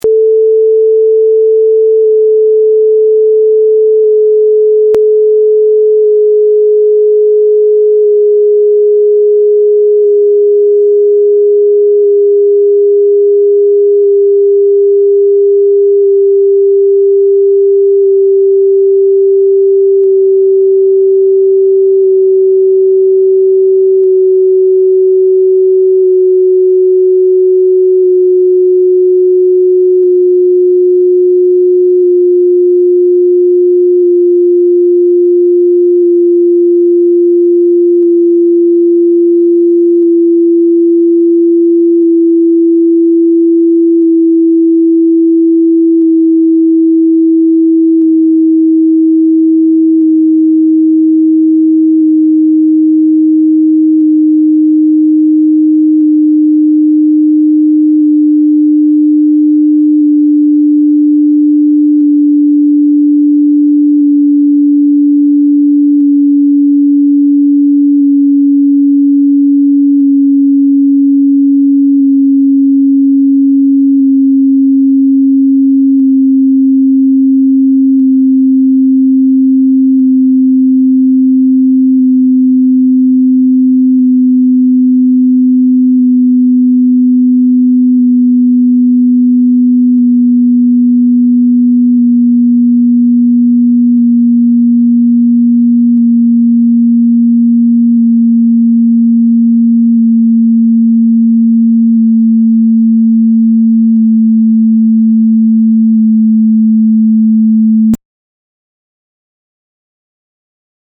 Mikrotonleitern / microtone scales
Die 6/53-teltonleiter / The 6/53 tone scale (sound file)
6/53-tone, downwards, 53 steps